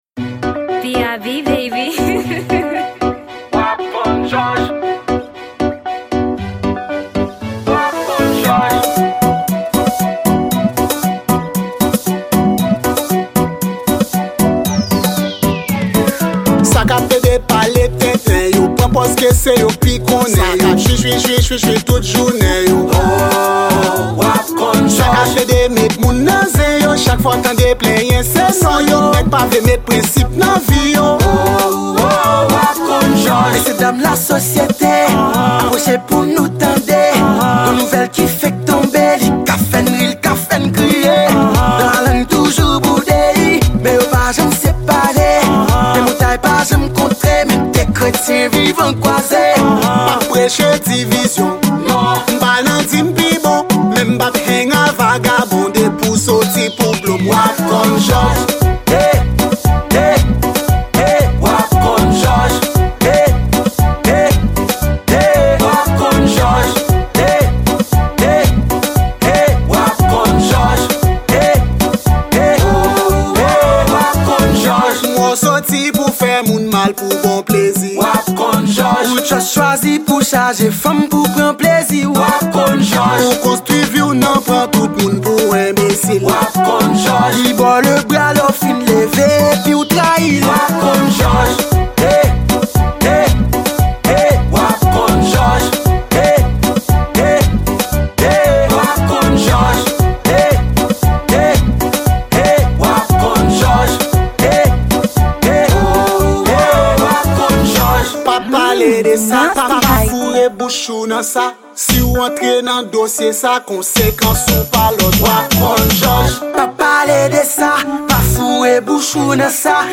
Genre: Roots.